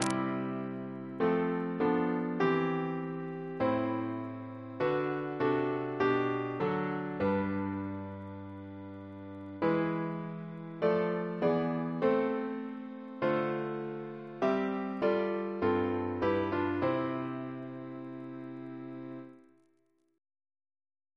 Double chant in C Composer: John Frederick Bridge (1844-1924) Reference psalters: RSCM: 24